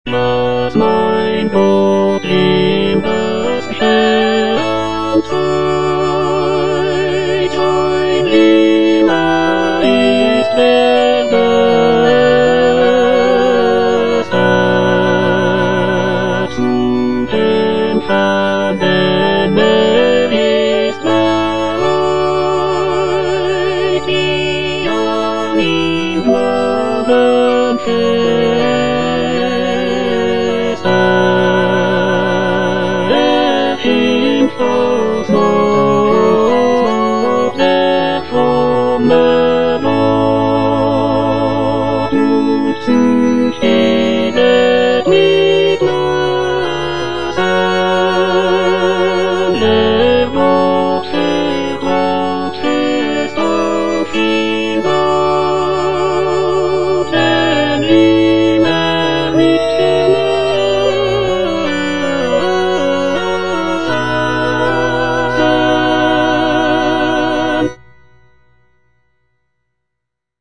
Cantata
Tenor (Emphasised voice and other voices) Ads stop